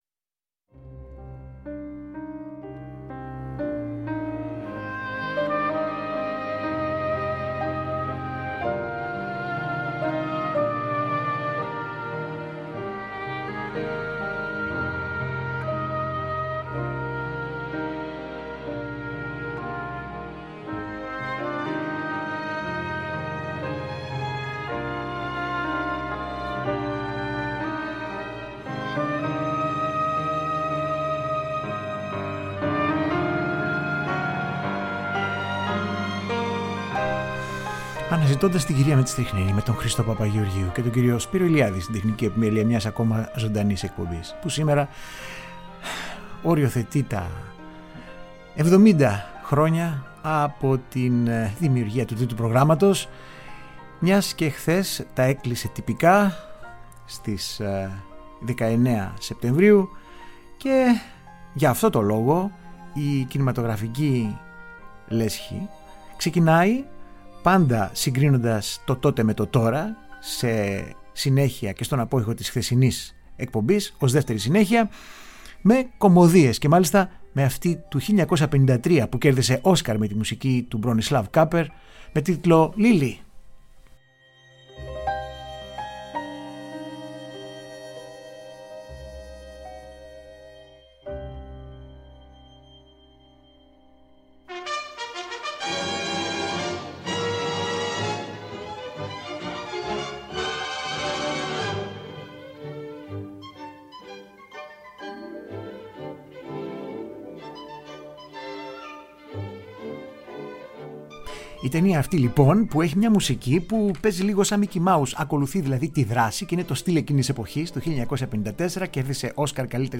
Μια γρήγορη περιήγηση μέσα στο χρόνο για τις εμβληματικές μουσικές που κέρδισαν τη διάκριση ανά δεκαετία από την 1η χρονιά που θεσμοθετήθηκαν τα βραβεία Όσκαρ για την καλύτερη μουσική επένδυση μέχρι και σήμερα. Μια καλειδοσκοπική ακουστική προσέγγιση στην εξέλιξη της κινηματογραφικής μουσικής γλώσσας που μιλάει από μόνη της για τον εαυτό της.